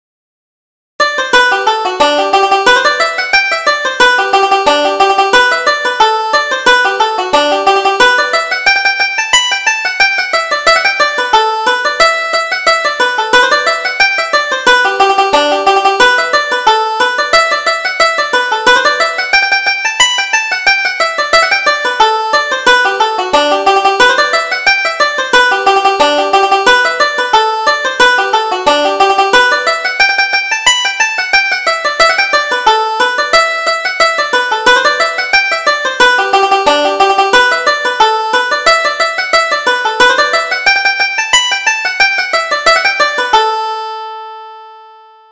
Reel: The Honeymoon Reel